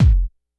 Kick_49.wav